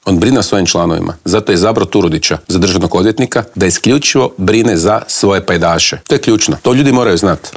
"Na idućim parlamentarim izborima SDP će biti prvi, kandidirao sam se za predsjednika stranke da budem premijer", otkrio nam je u Intervjuu tjedna Media servisa predsjednik najjače oporbene stranke Siniša Hajdaš Dončić.